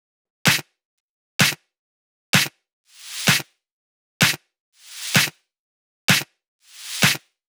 Index of /VEE/VEE Electro Loops 128 BPM
VEE Electro Loop 150.wav